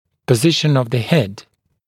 [pə’zɪʃn əv ðə hed][пэ’зишн ов зэ хэд]положение головы